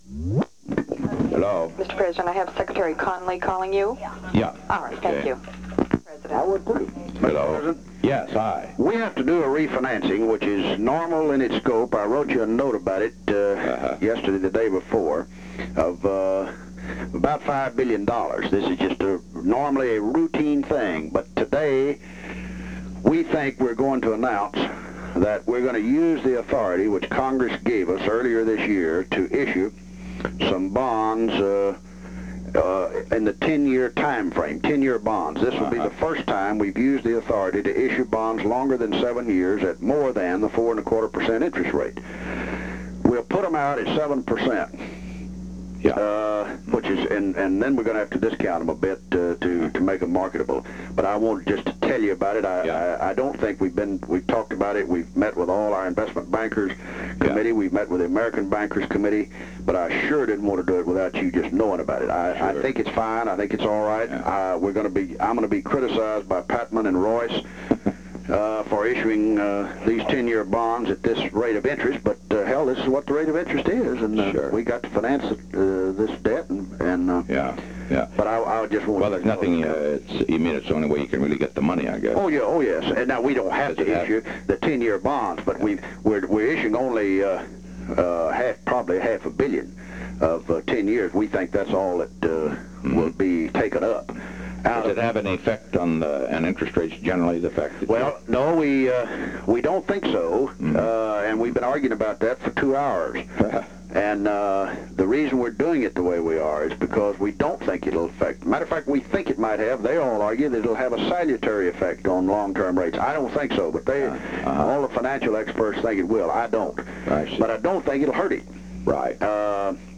Secret White House Tapes
Location: White House Telephone
John B. Connally talked with the President.